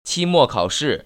[qīmò kăoshì] 치모카오스  ▶